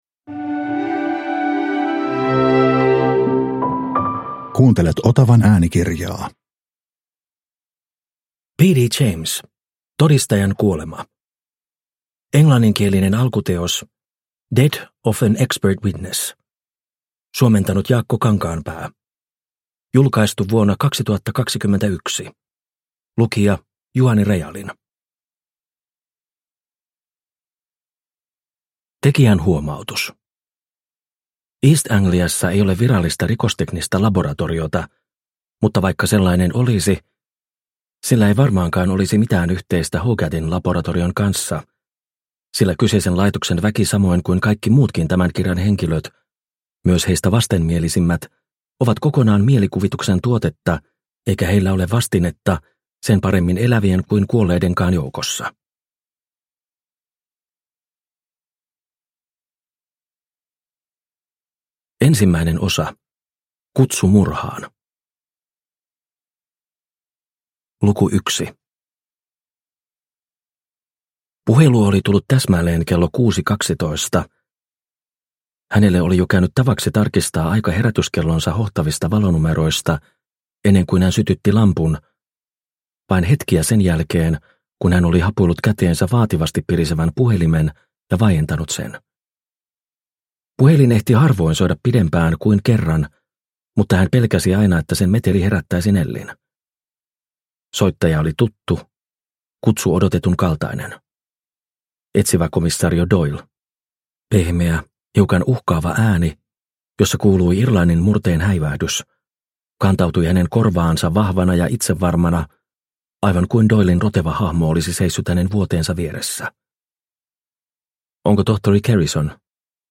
Todistajan kuolema – Ljudbok – Laddas ner